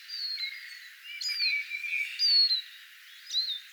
ilmeisesti keltanokkarastaan
erikoinen pikkusäe
ilmeisesti_keltanokkarastaan_erikoinen_sae.mp3